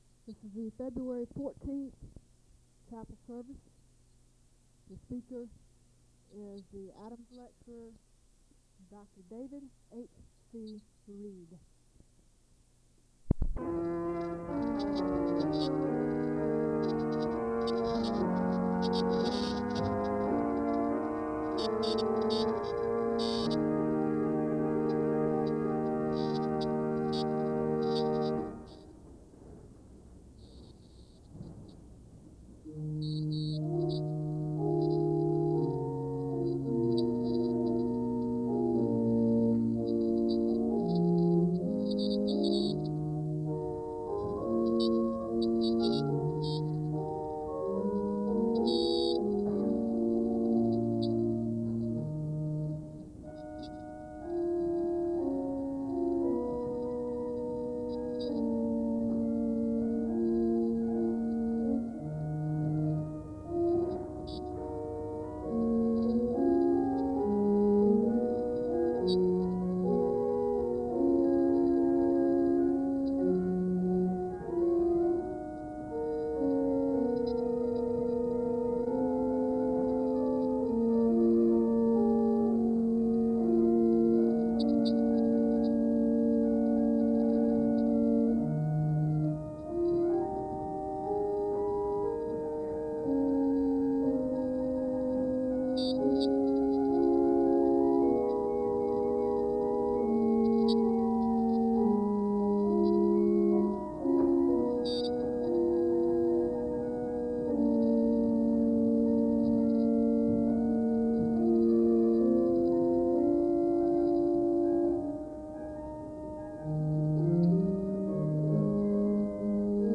The service begins with organ music and a moment of prayer (0:00-6:10).
The choir sings an anthem (8:11-10:48).
The service ends with a blessing (56:12-56:35).
Location Wake Forest (N.C.)